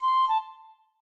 flute_c1a.ogg